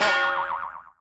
File:Gong Boing.oga
Gong with boing sound.
Gong_Boing.oga.mp3